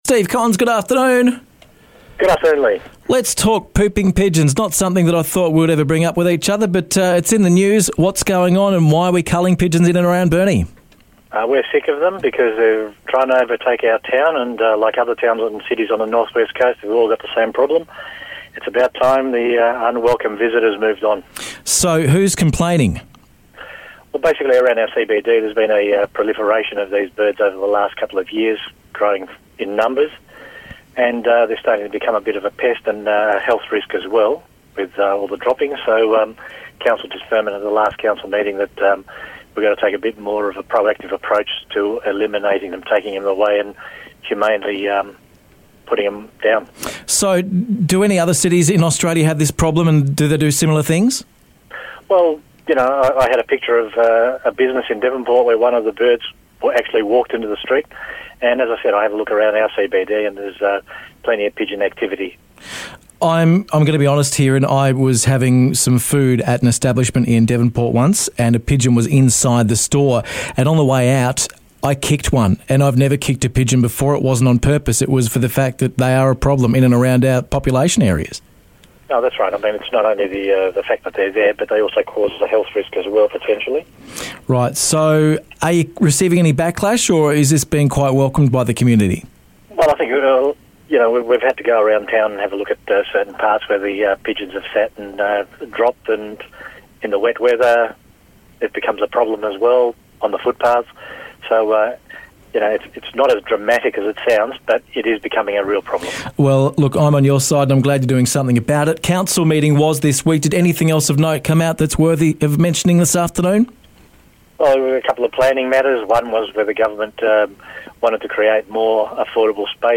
Pooping pigeons to be culled in Burnie. Burnie Mayor Steve Kons explains why.